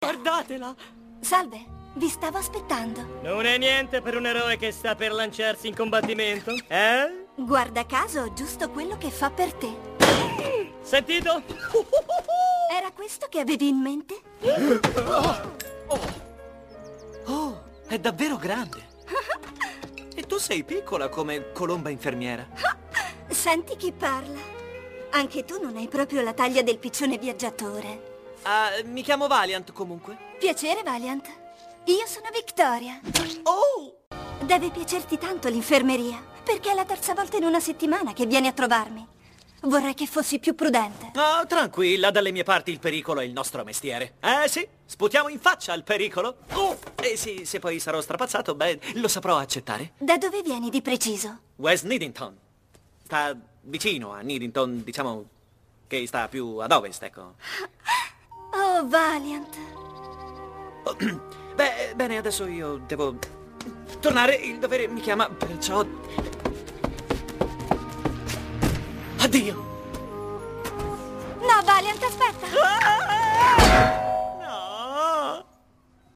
FILM D'ANIMAZIONE (CINEMA E HOME-VIDEO)
• "Valiant - Piccioni da combattimento" (Voce di Victoria)